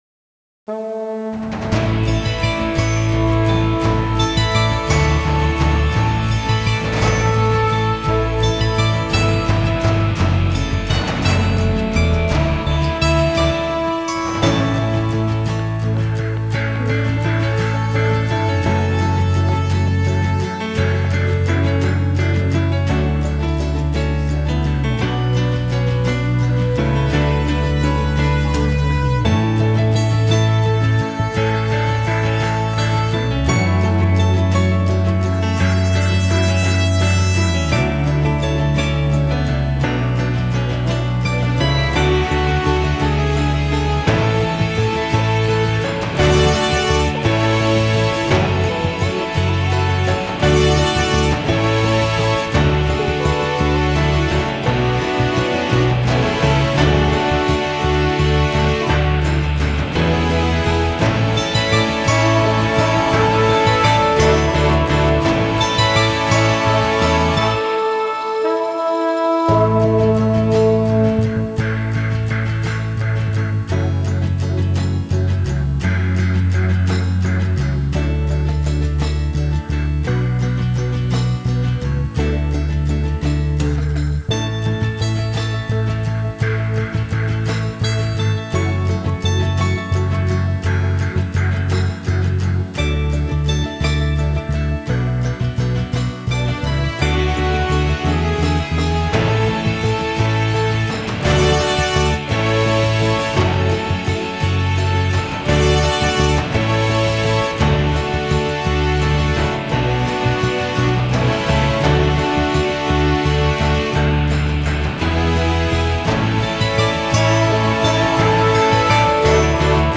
MUSIC AUDIO ONLY
ItsGod_TRAX_Traditional.wav